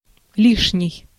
Ääntäminen
IPA : /əkˈsɛs/ IPA : /ˈɛksɛs/